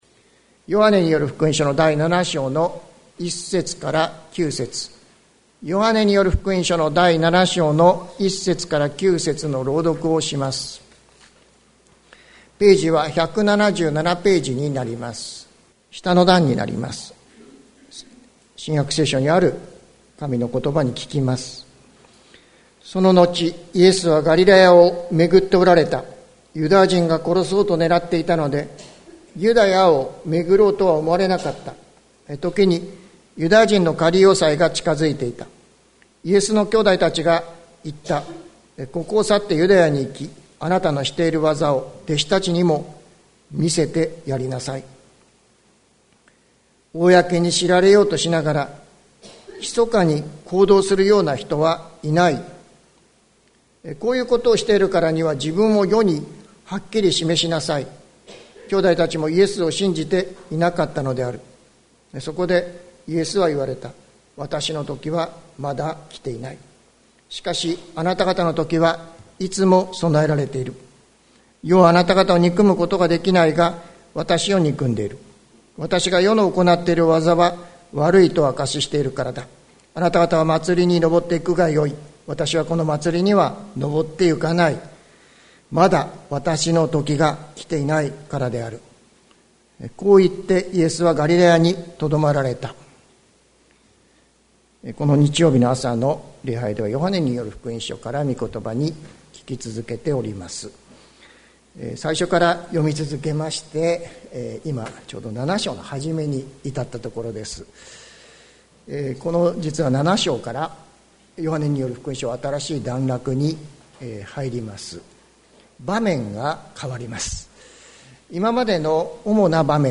2022年06月19日朝の礼拝「そっと伝えます」関キリスト教会
説教アーカイブ。